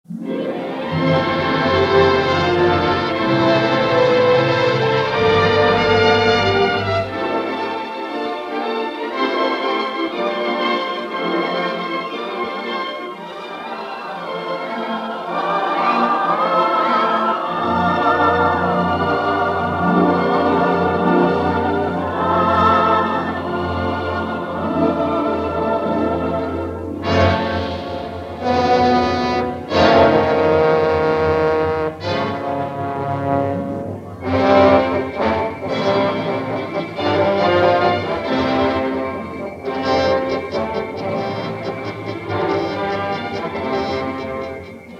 a gorgeous score brimming with sympathy and melancholy.
The sound quality is largely "archival,"